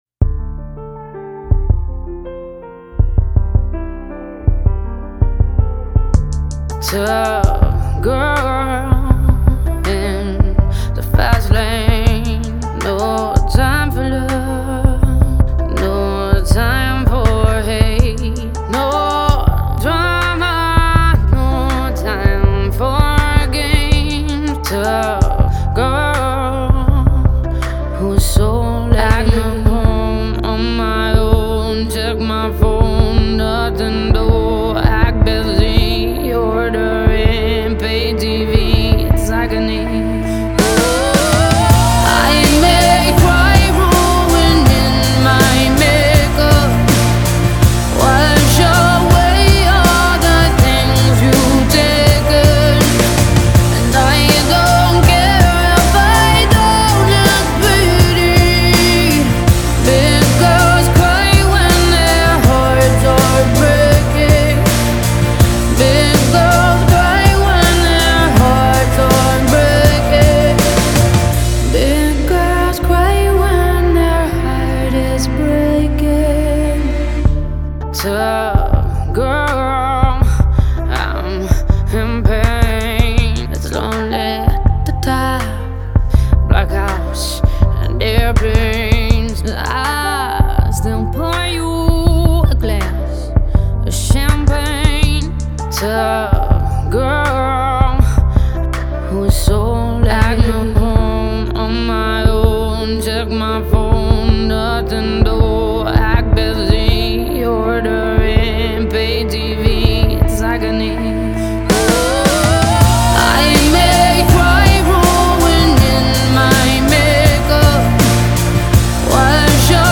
Лучшие медляки
медленная музыка